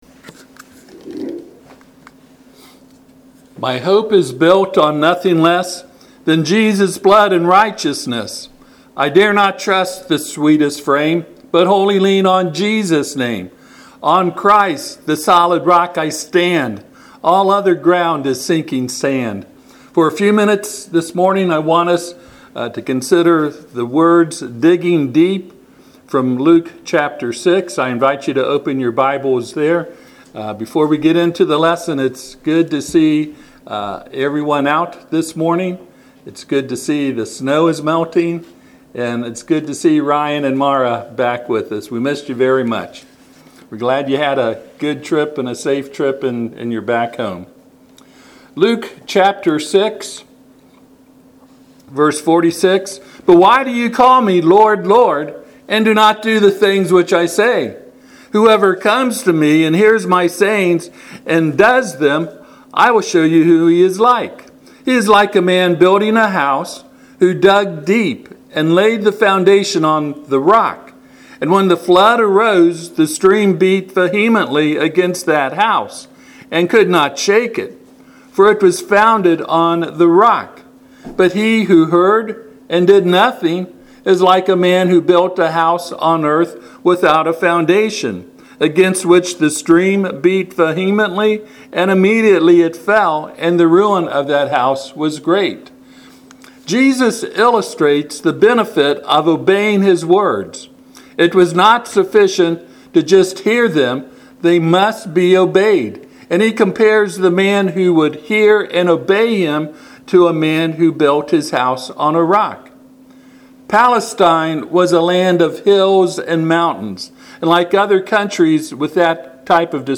Passage: Luke 6:48 Service Type: Sunday AM